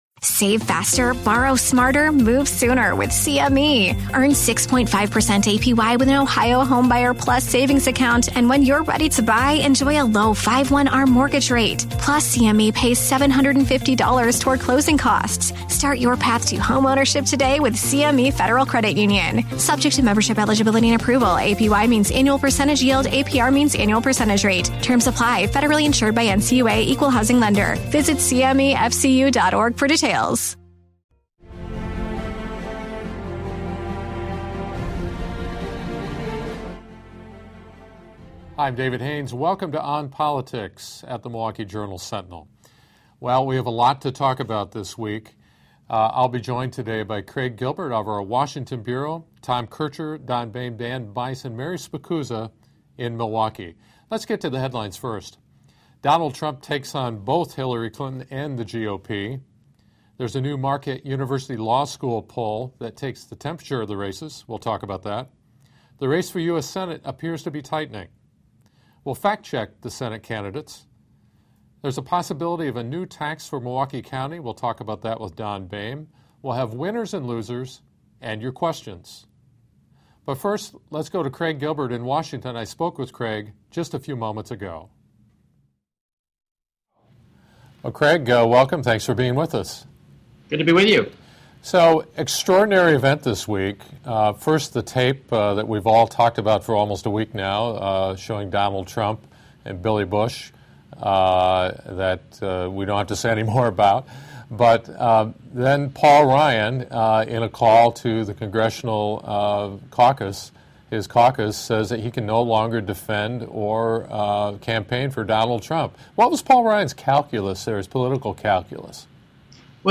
The panel discusses the latest MU poll results and what the results say about the presidential and Senate races. We'll also explain Chris Abele's proposed wheel tax.